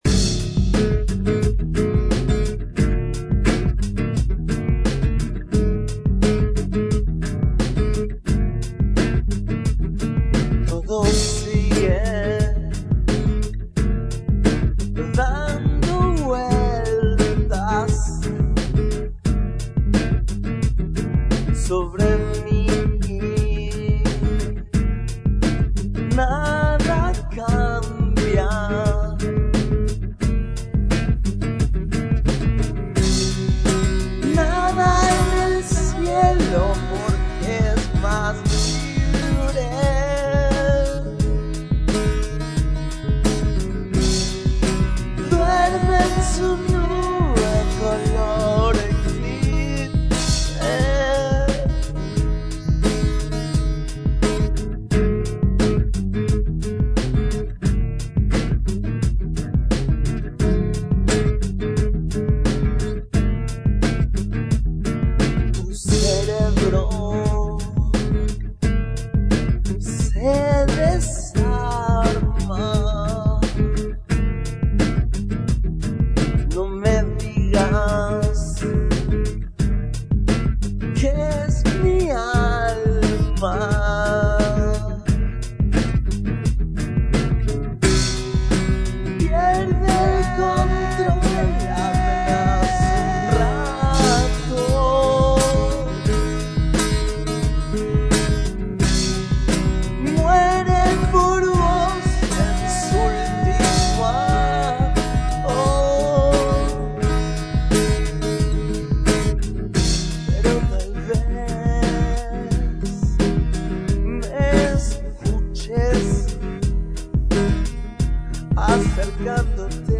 Los audios no son muy buenos enalgunos casos y son solo ideas de temas.
Una canción que compuso en el viejo patio trasero del comité en San Telmo, ahí donde tuvo la primer sala de ensayo, un par de amores, y mucho rock&roll.